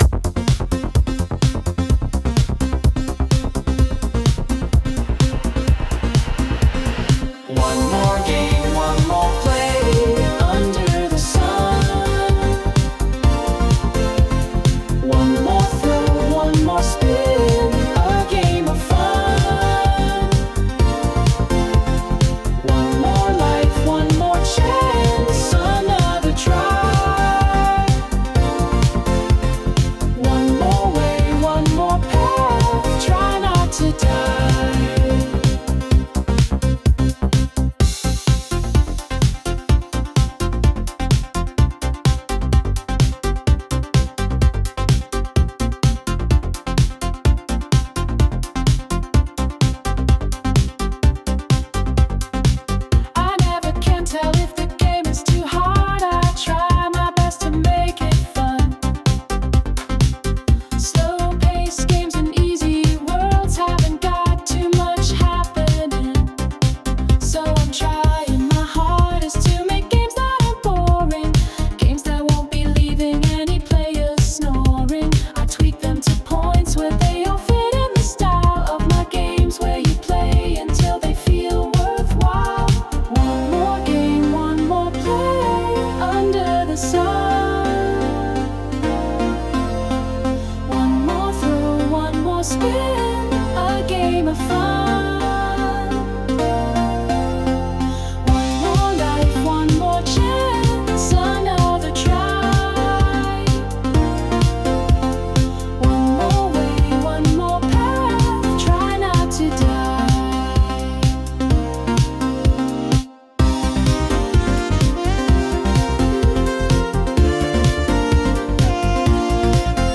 Sung by Suno
One_More_Spin_(Remix)_mp3.mp3